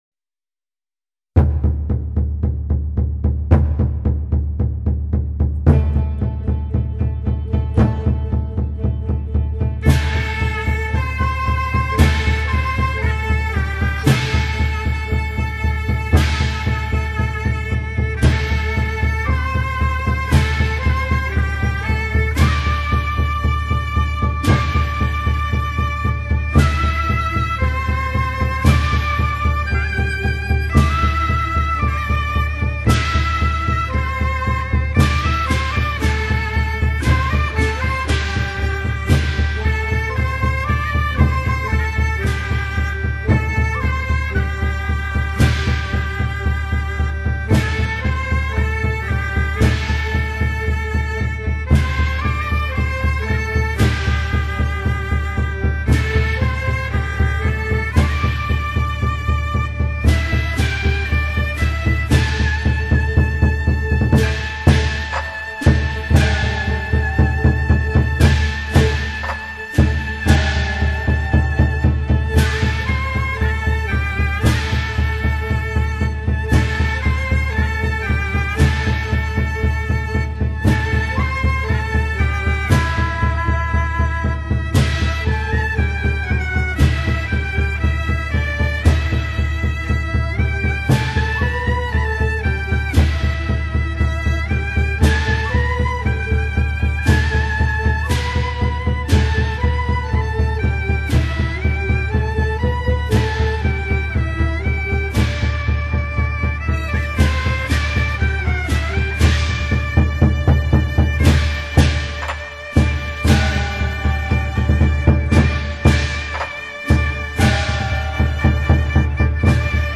广东音乐专辑
发挥了广东音乐华丽抒情、轻灵活泼之特色